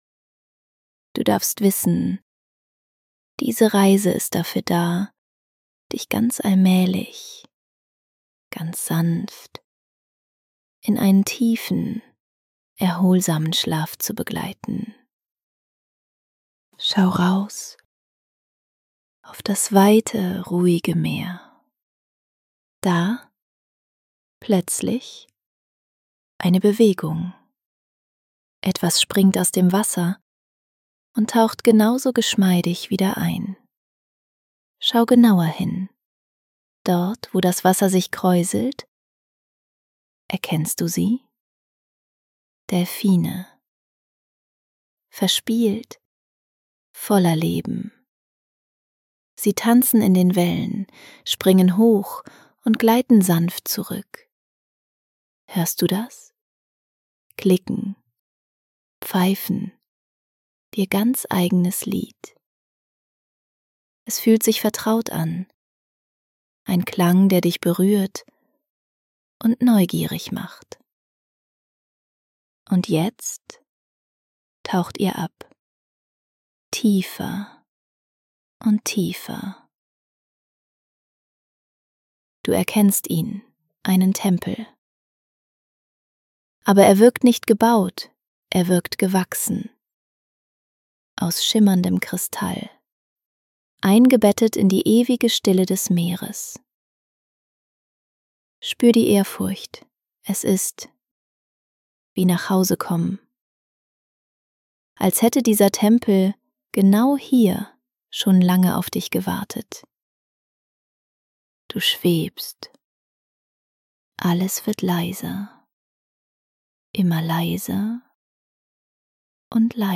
Lass dich von Cerebras einnehmender Stimme an einen Ort führen, an dem Zeit keine Rolle mehr spielt.
• Hypnotiseurin: KI-Coach Cerebra
• Hypnose-Einleitung: Konversationshypnose, Entspannung
• Stimme pur, auch über Lautsprecher wirksam